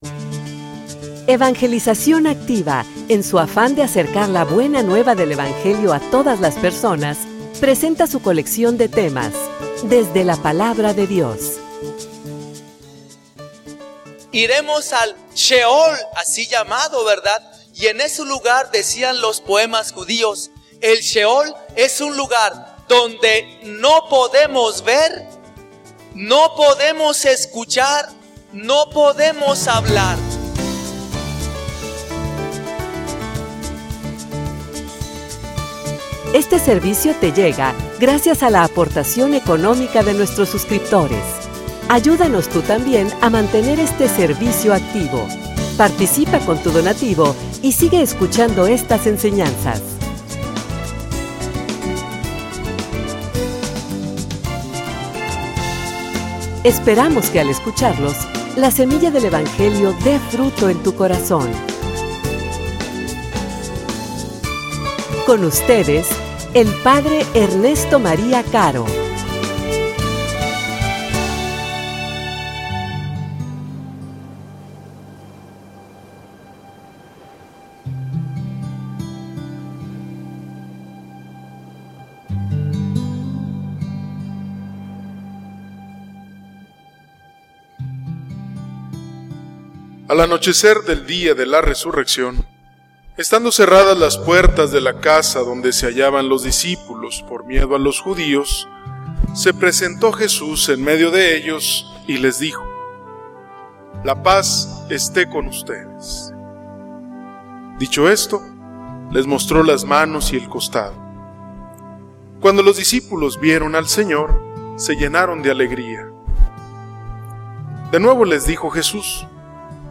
homilia_Shalom.mp3